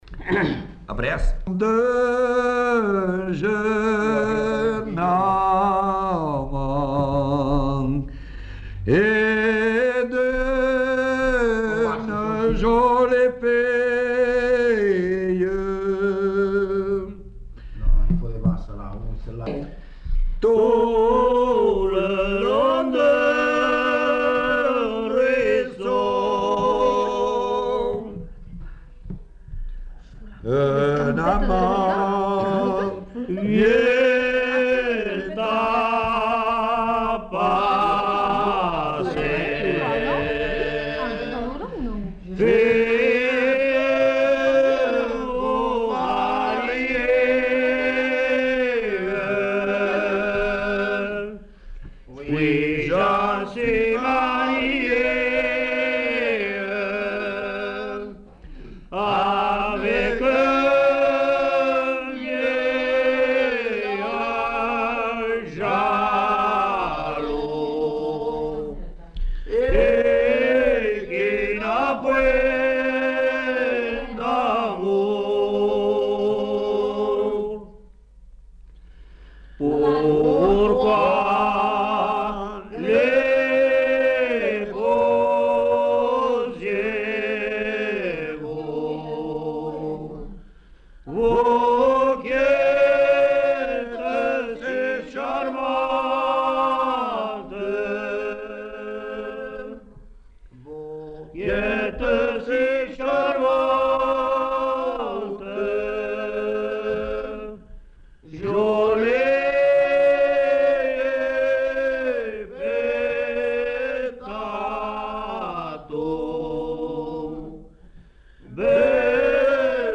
Groupe de chanteurs] |